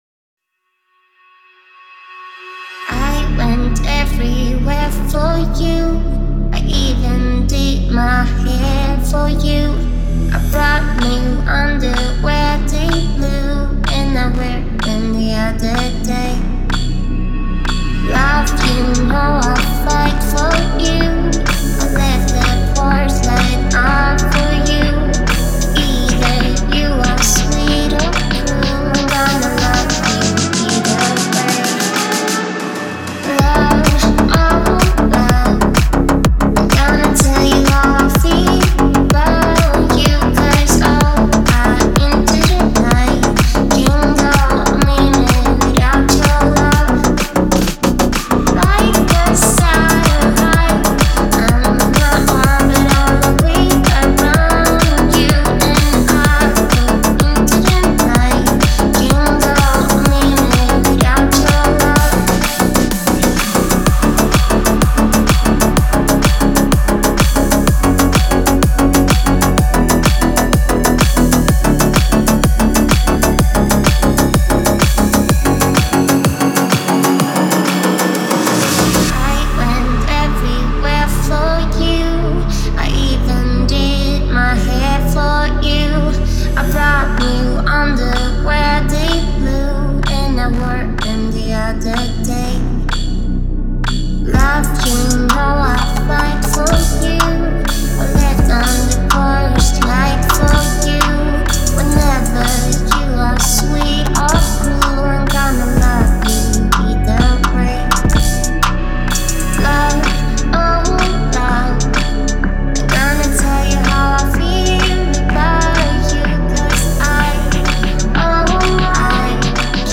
это завораживающая композиция в жанре электро-поп